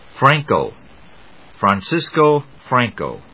音節Fran・co 発音記号・読み方/frˈæŋkoʊ‐kəʊ/, Francisco /frænsískoʊ‐kəʊ/発音を聞く